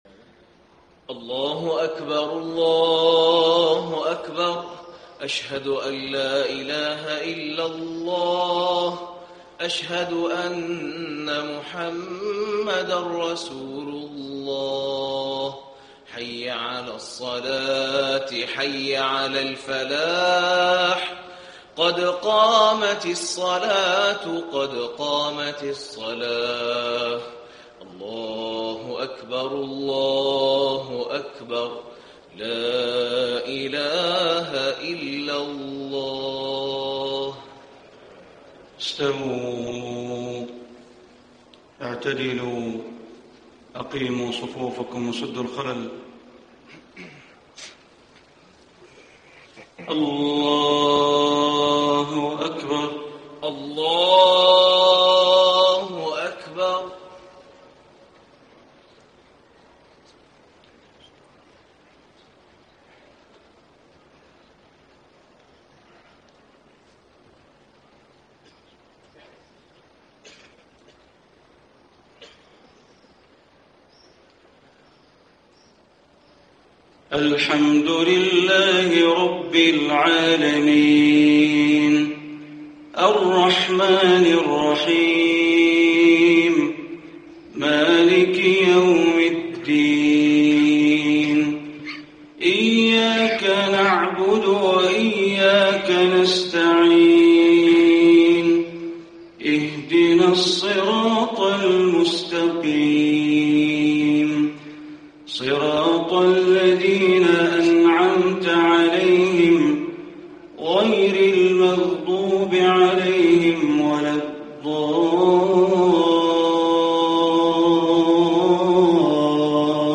صلاة الفجر 3-6-1435 ما تيسر من سورة الرحمن > 1435 🕋 > الفروض - تلاوات الحرمين